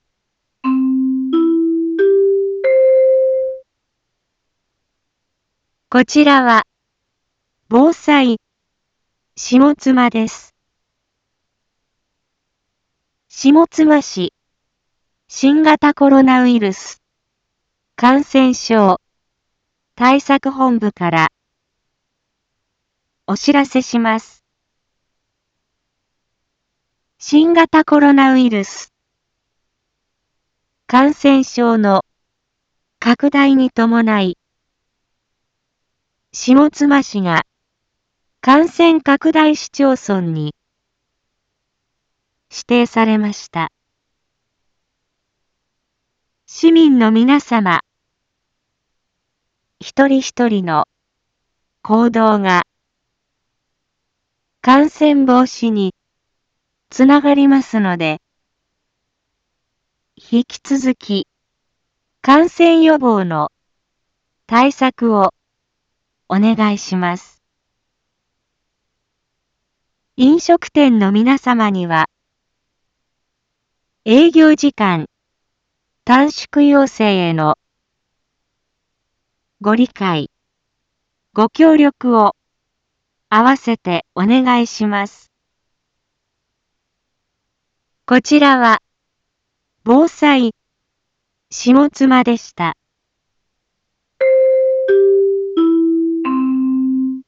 Back Home 一般放送情報 音声放送 再生 一般放送情報 登録日時：2021-05-20 18:31:40 タイトル：新型ｺﾛﾅｳｲﾙｽ感染症にかかる注意喚起 インフォメーション：こちらは、防災下妻です。